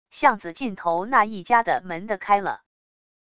巷子尽头那一家的门的开了wav音频生成系统WAV Audio Player